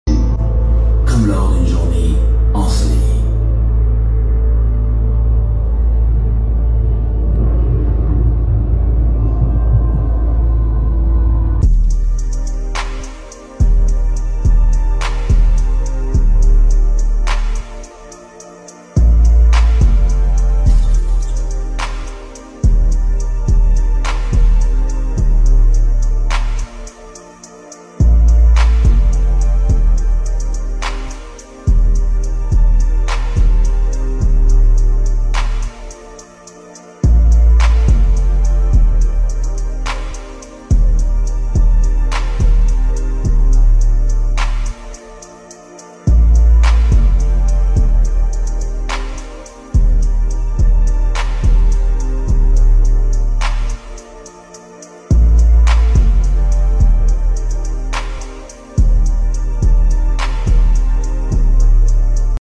A Mp3 Sound Effect Immersive evening with Phosphen! A pleasure to have created the sound universe for this immersive dome...